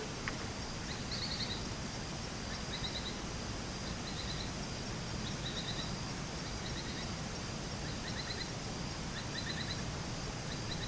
Pijuí Plomizo (Synallaxis spixi)
Nombre en inglés: Spix´s Spinetail
Fase de la vida: Adulto
Localidad o área protegida: Parque Nacional Ciervo de los Pantanos
Condición: Silvestre
Certeza: Observada, Vocalización Grabada